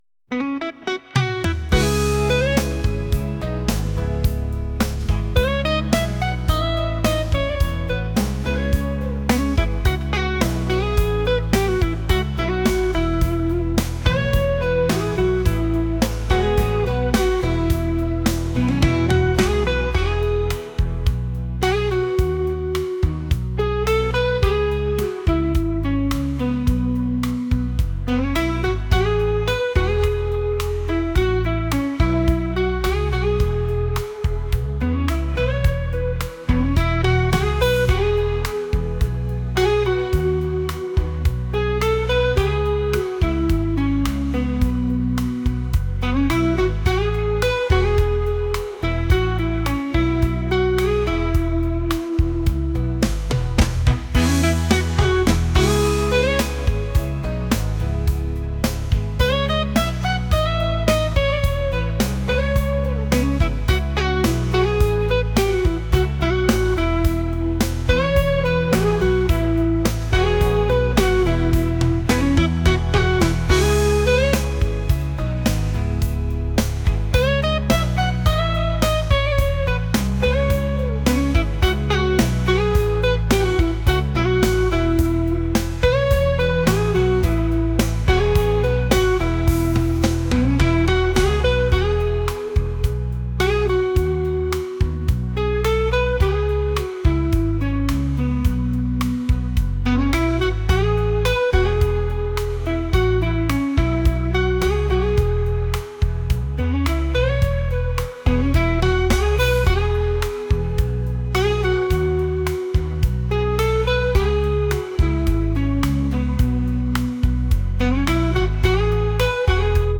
pop | soul & rnb | lounge